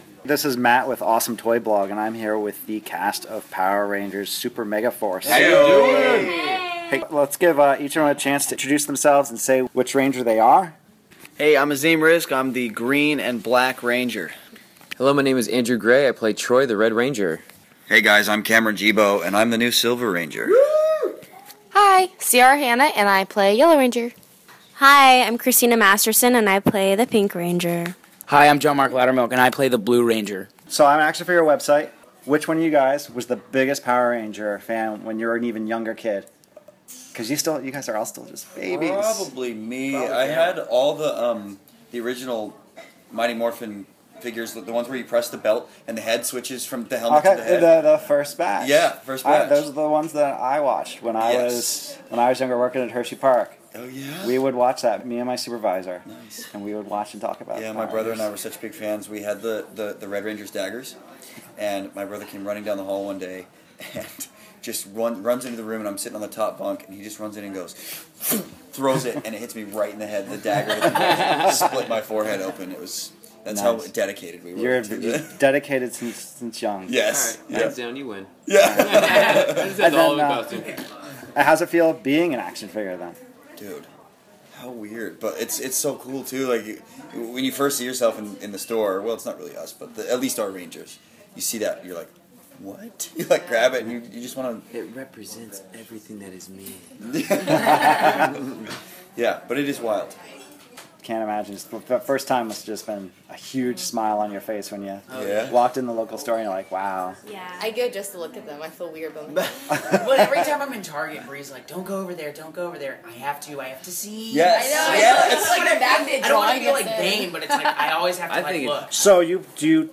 SDCC 2014 – Interview with the Cast of Power Rangers Super Megaforce
Power-Rangers-Interview-final.mp3